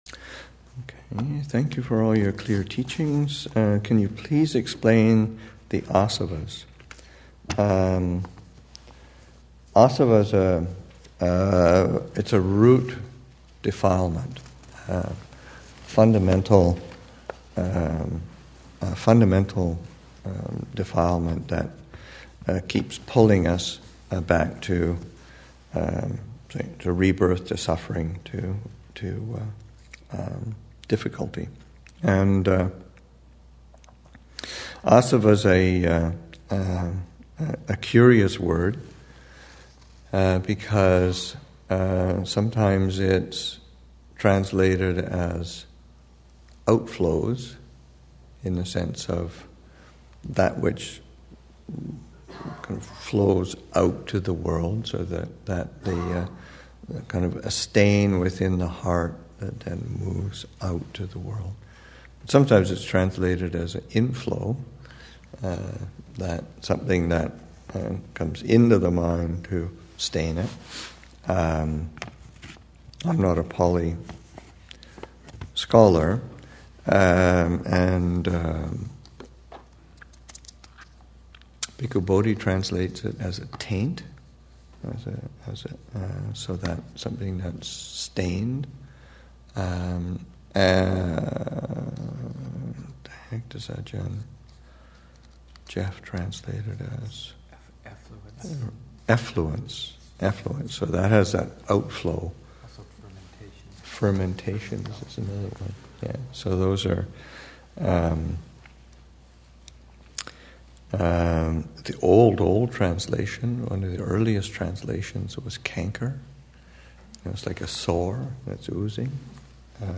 2015 Thanksgiving Monastic Retreat, Session 6 – Nov. 26, 2015